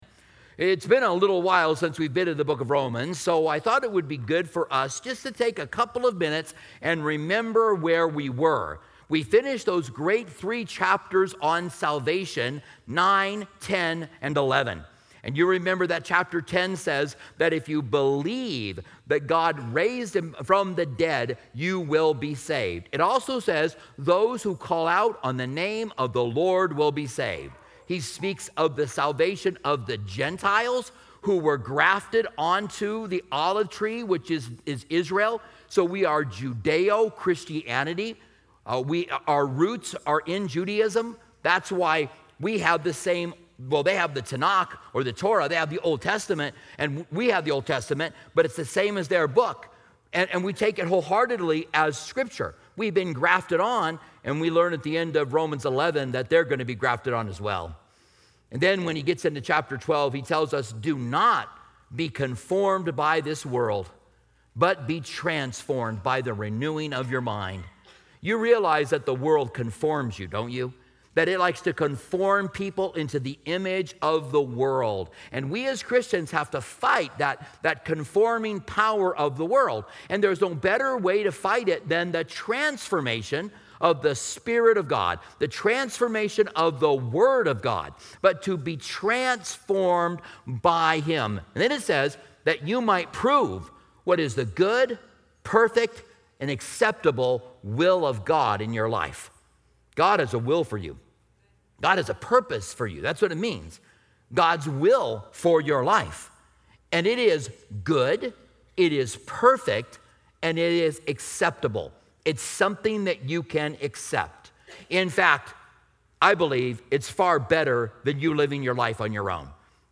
Join us for an impactful sermon exploring Romans 12:9-21, where the Apostle Paul provides a profound guide to Christian living. This Bible study emphasizes the importance of living a transformed life through sincere love, humility, and service, while resisting worldly pressures. Key topics include the transformative power of God’s Word, genuine relationships within the Church, perseverance in faith, and the call to bless those who oppose us.